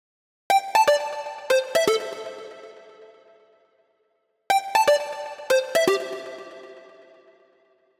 23 MonoSynth PT2.wav